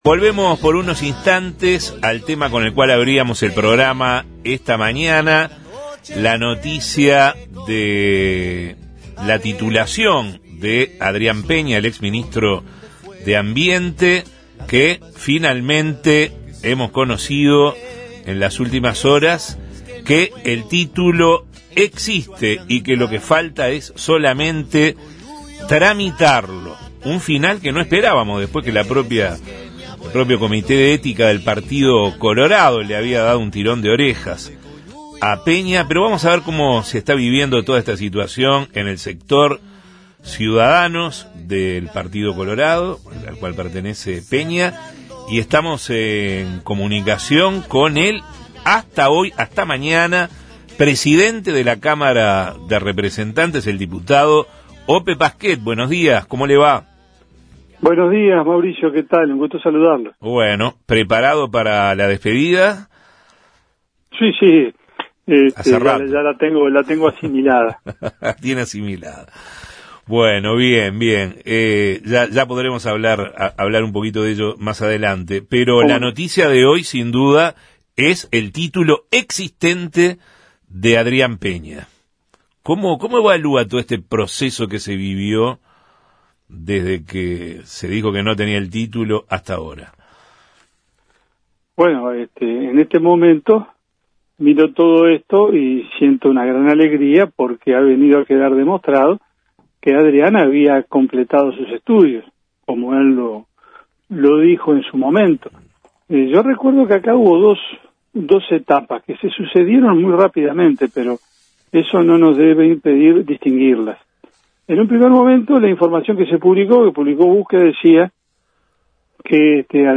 En diálogo con Justos y Pecadores, el diputado colorado se mostró satisfecho con la confirmación de que Peña efectivamente hizo el curso que le faltaba para terminar la carrera de Administración de Empresas, tras la revisión del caso y la correspondiente rectificación por parte de la Universidad Católica.